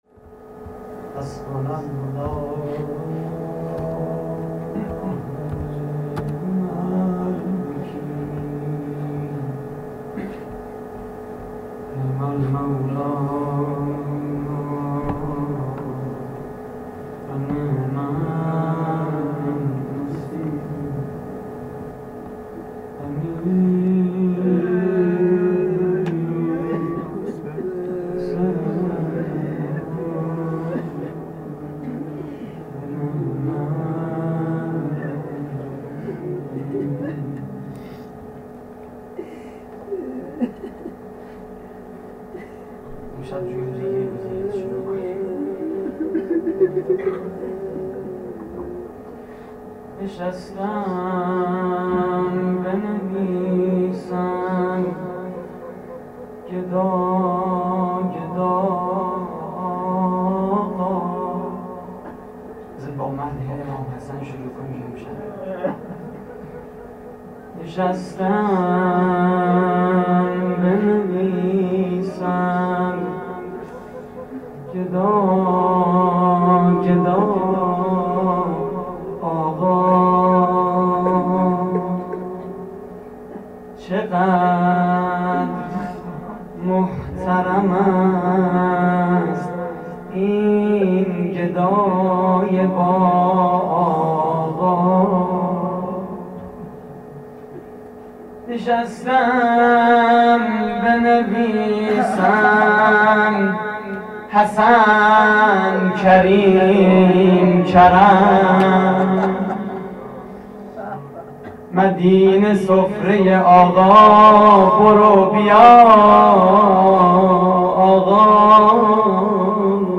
روضه: نشسته‌ام بنویسم گدا گدا آقا
مراسم عزاداری شب ششم محرم 1432